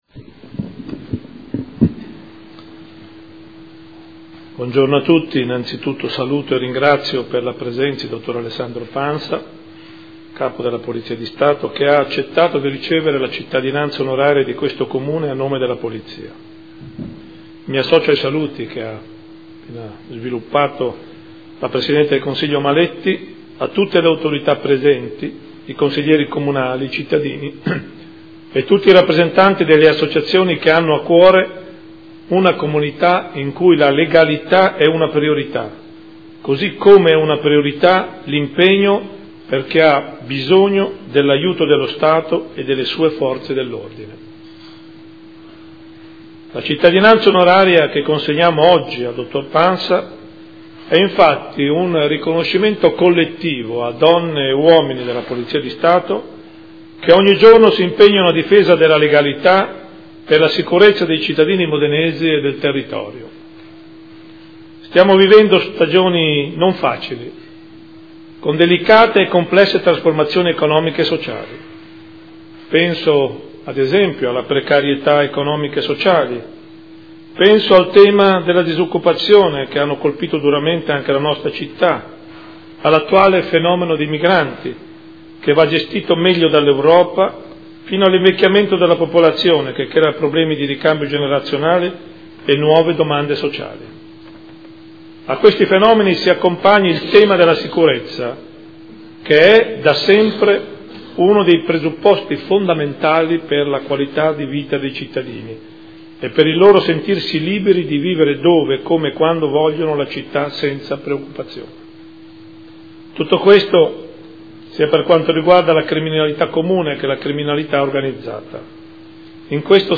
Seduta del 21/09/2015.